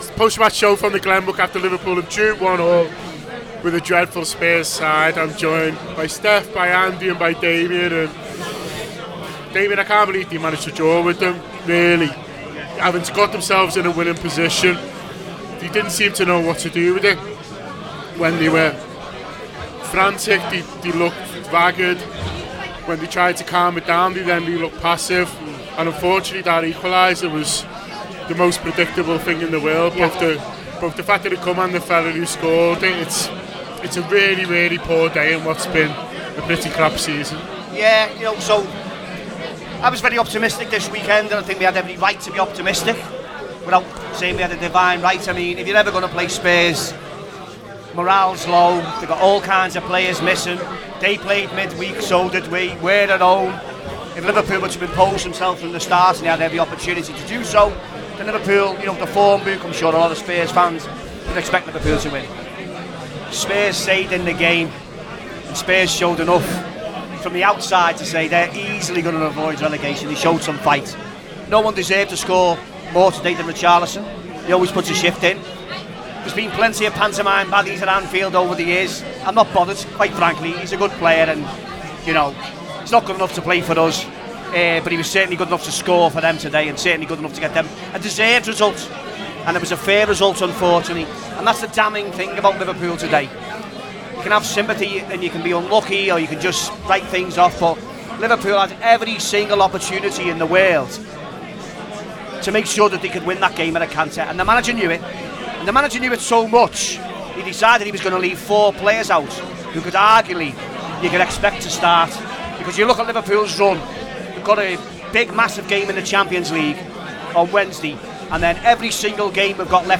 The Anfield Wrap’s post-match reaction podcast after Liverpool 1 Tottenham 1 in the Premier League at Anfield.